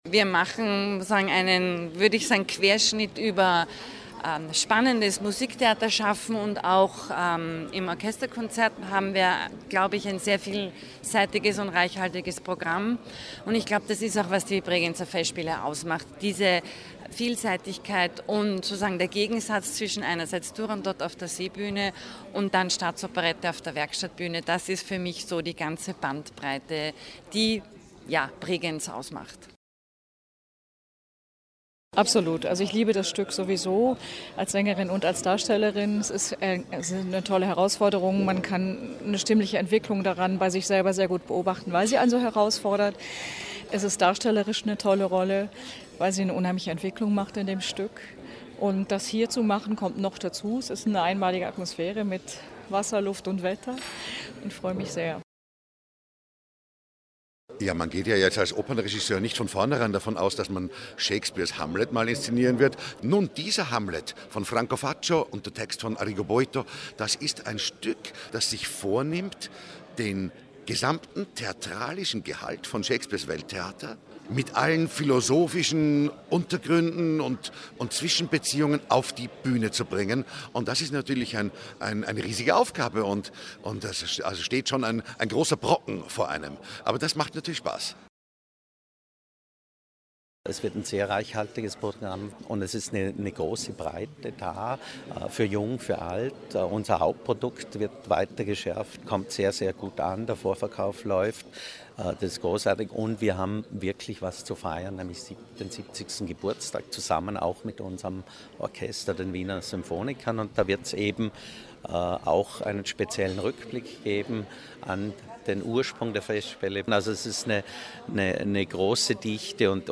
Pressekonferenz Programmpräsentation 2016 - O-Ton - feature